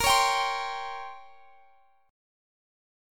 Bbm6add9 chord